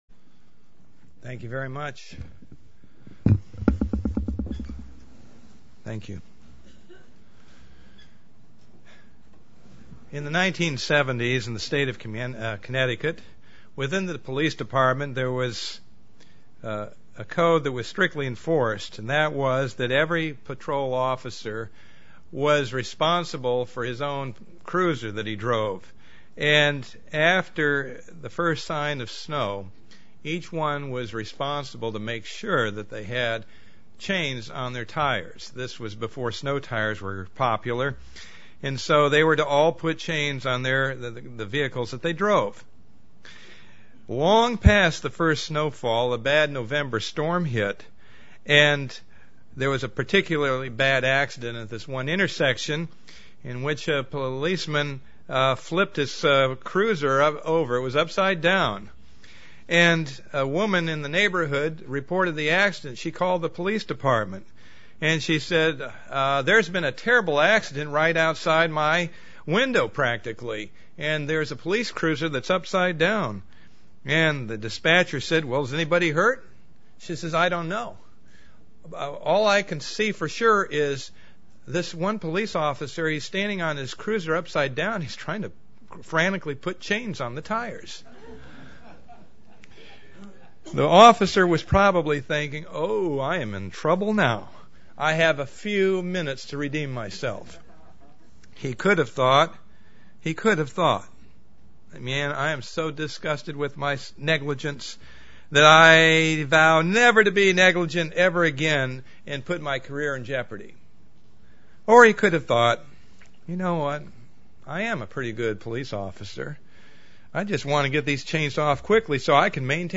Given in Tampa, FL
Print We need to set aside the time to make sure we are truly connected to God UCG Sermon Studying the bible?